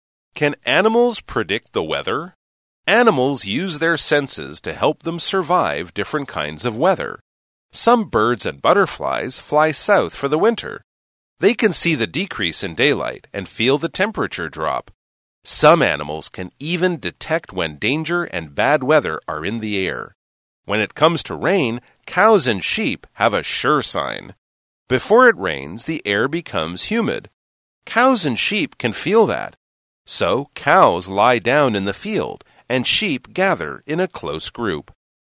朗讀題目及練習音檔請參閱附加檔案~~